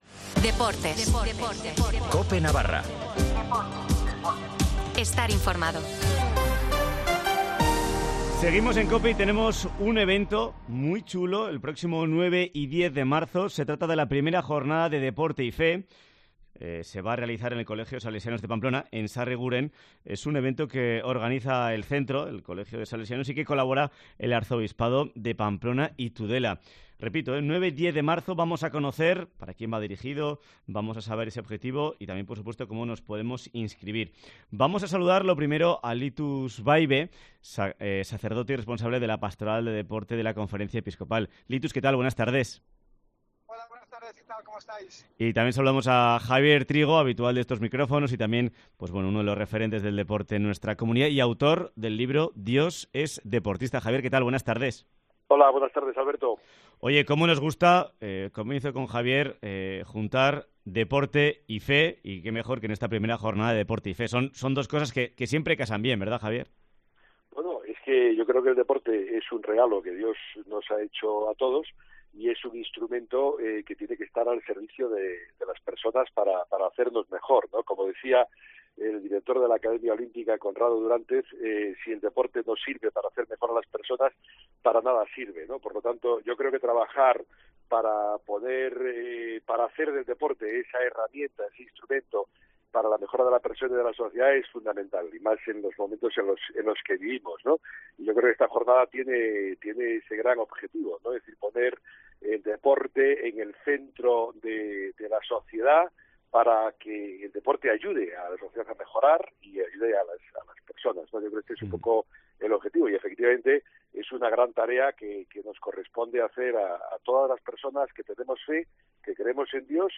Entrevista en COPE Navarra de la 'I Jornada Deporte y Fe'